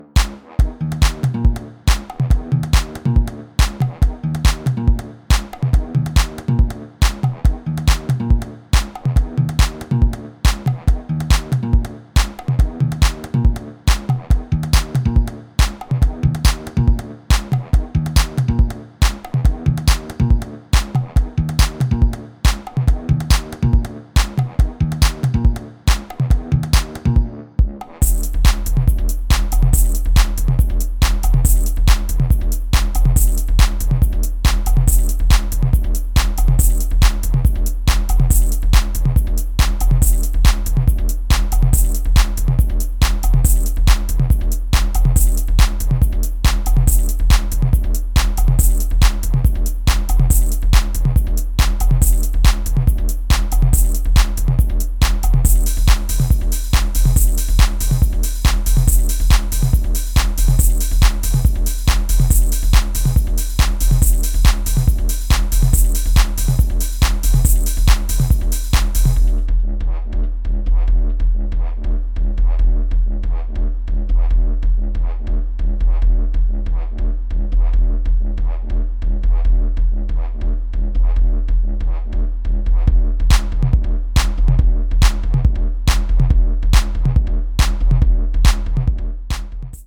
ghetto house